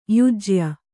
♪ yajya